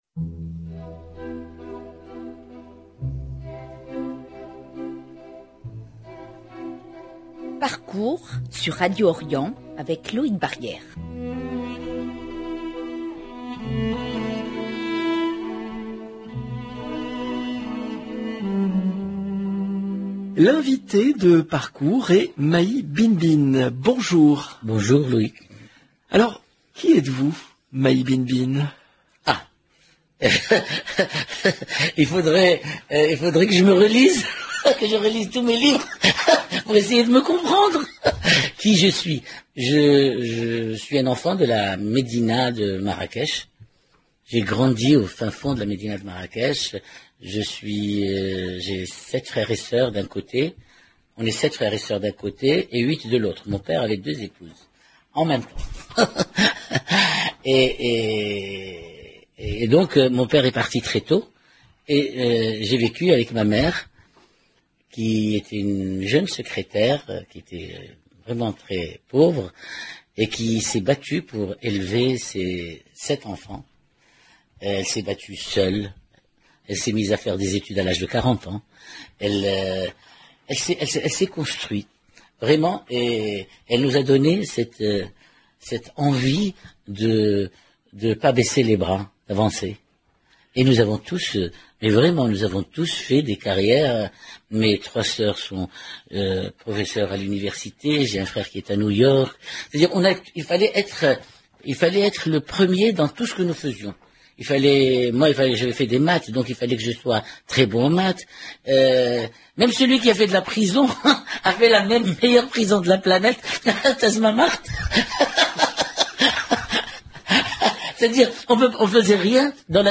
L'écrivain et peintre Mahi Binebine est l'invité de Parcours